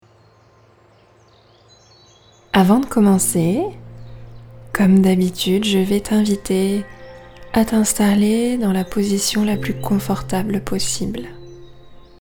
resonance-extrait-14-voix-parlee.mp3